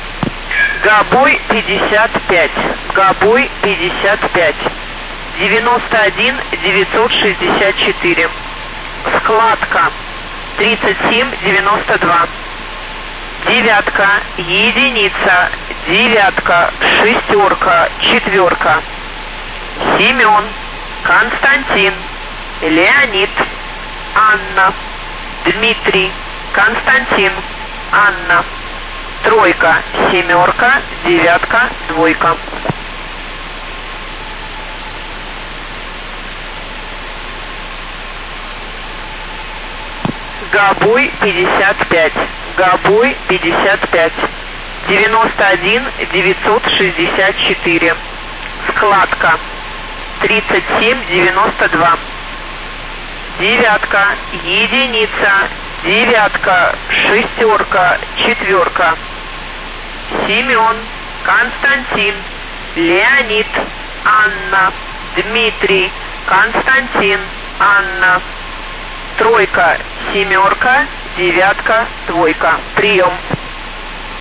• J3E (USB)